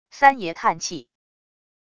三爷叹气wav音频